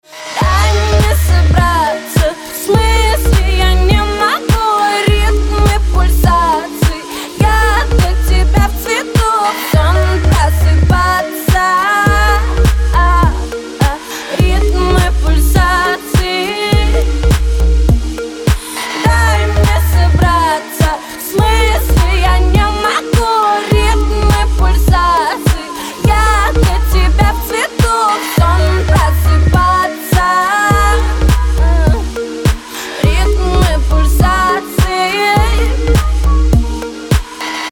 женский вокал
dance
медленные
RnB